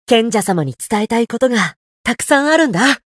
觉醒语音 有很多想告诉贤者大人的事 賢者様に伝えたいことが、たくさんあるんだ 媒体文件:missionchara_voice_477.mp3